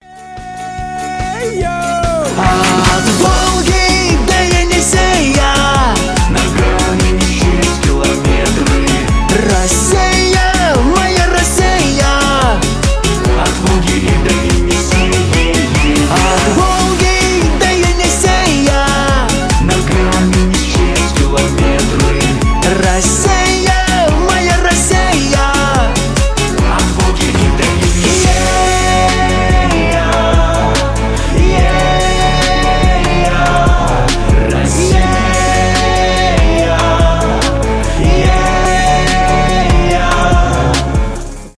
патриотические
cover
поп